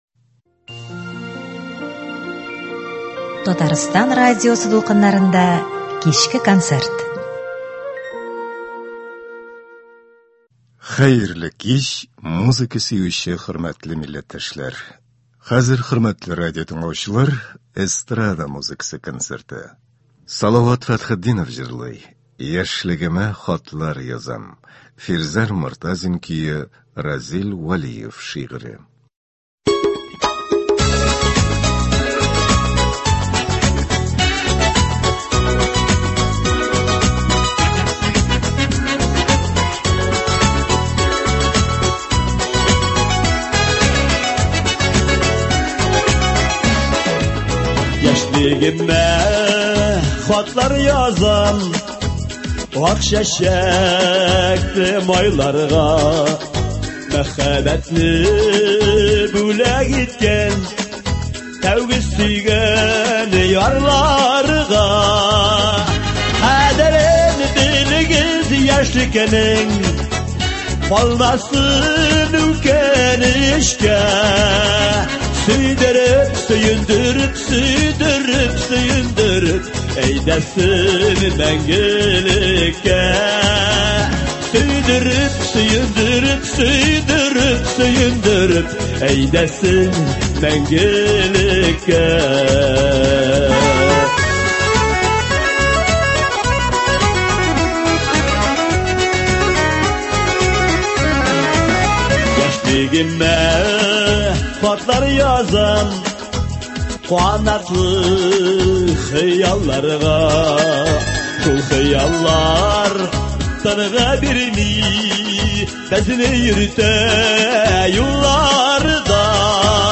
Эстрада музыкасы концерты.